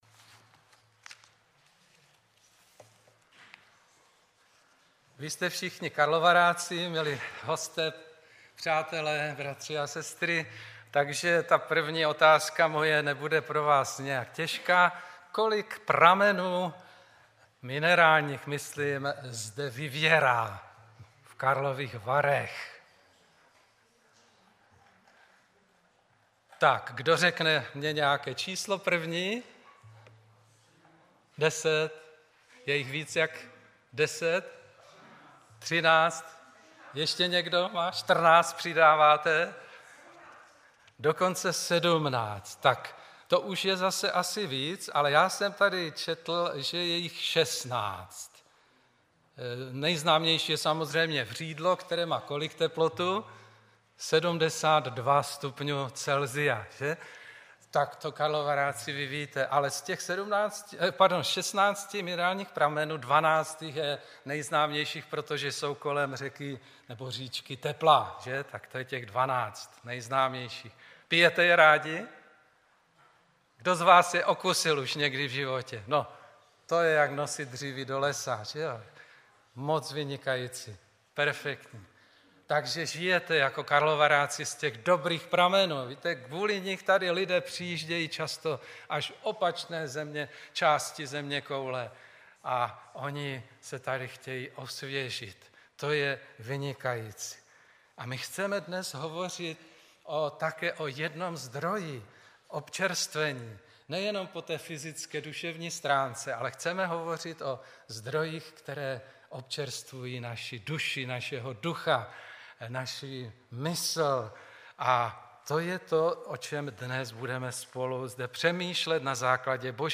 Twitter Digg Facebook Delicious StumbleUpon Google Bookmarks LinkedIn Yahoo Bookmarks Technorati Favorites Tento příspěvek napsal admin , 18.9.2016 v 18:14 do rubriky Kázání .